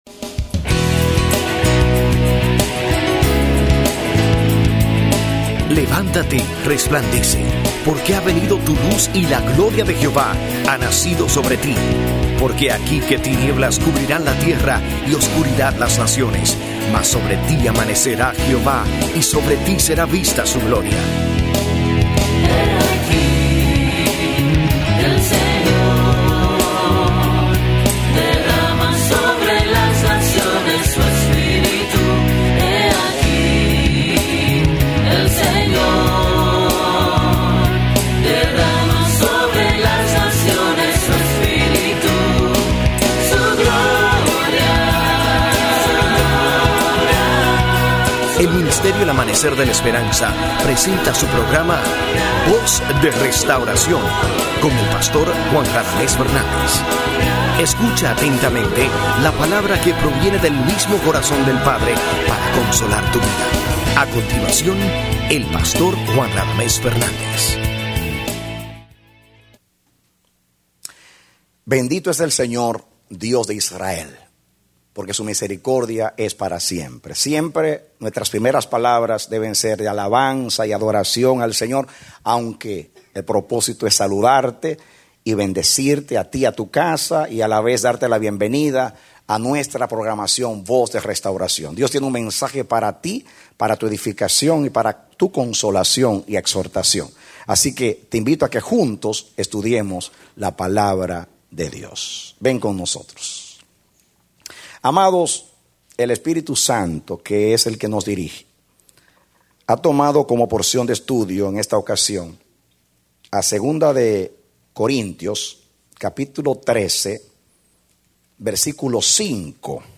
A mensaje from the serie "Mensajes." Predicado Octubre 25, 2009